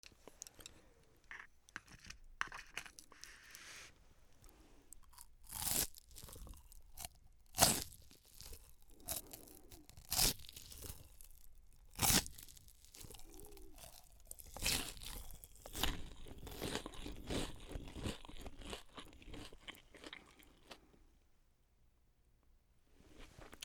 U87Ai